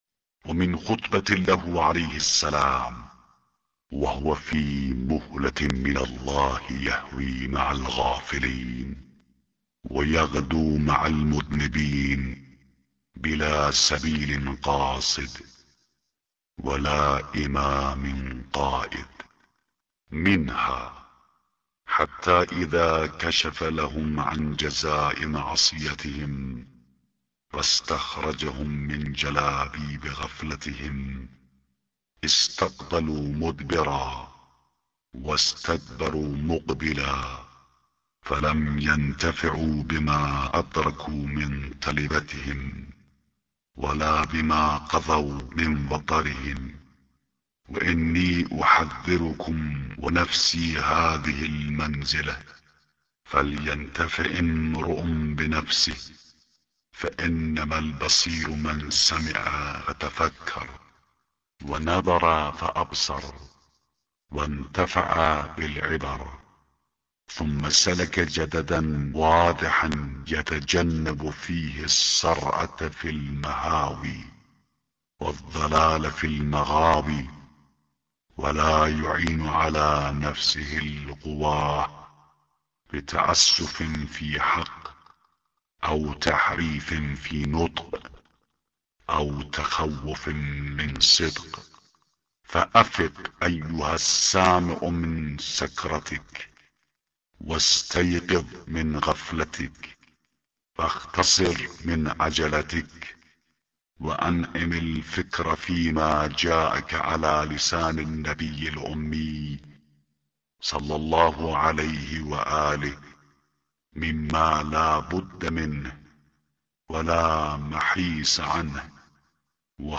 به گزارش وب گردی خبرگزاری صداوسیما؛ در این مطلب وب گردی قصد داریم، خطبه شماره ۱۵۳ از کتاب ارزشمند نهج البلاغه با ترجمه محمد دشتی را مرور نماییم، ضمنا صوت خوانش خطبه و ترجمه آن ضمیمه شده است: